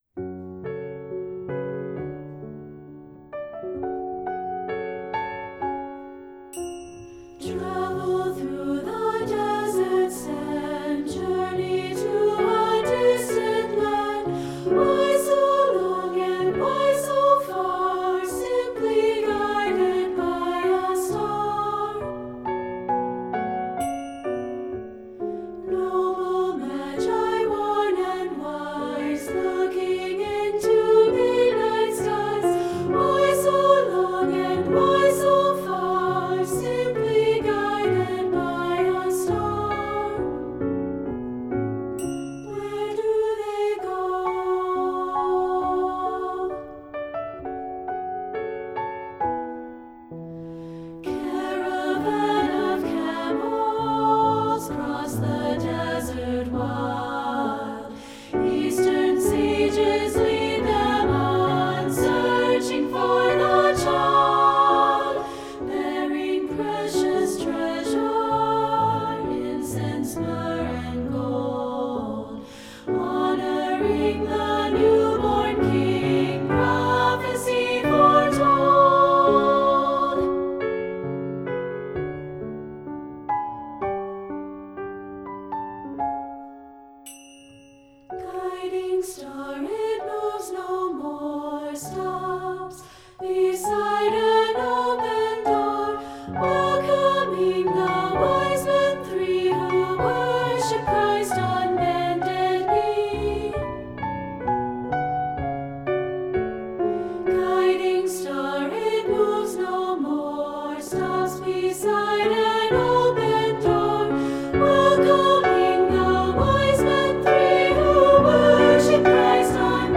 Voicing: Unison/two